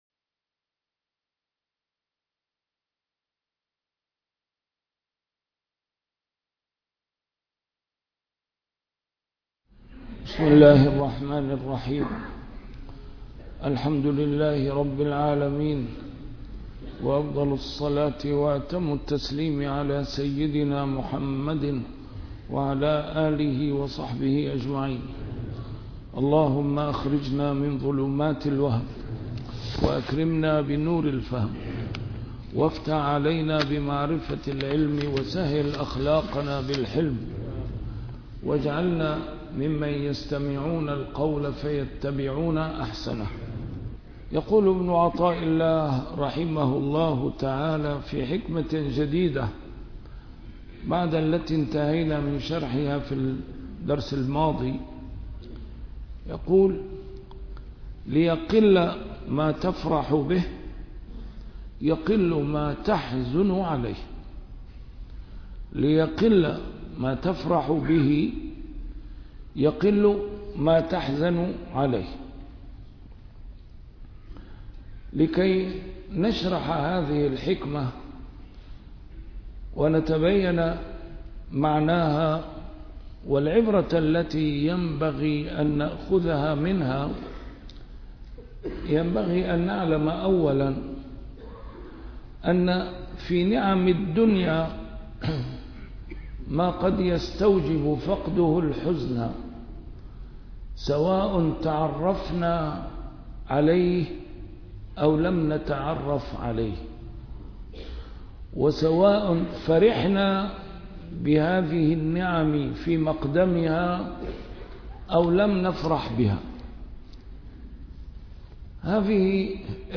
A MARTYR SCHOLAR: IMAM MUHAMMAD SAEED RAMADAN AL-BOUTI - الدروس العلمية - شرح الحكم العطائية - الدرس رقم 247 شرح الحكمة رقم 225